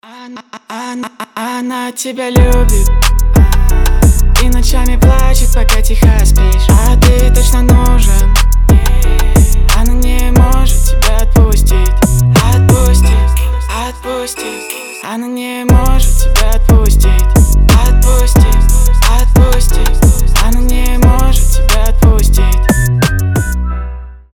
свист